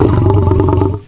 Q_GURGLE.mp3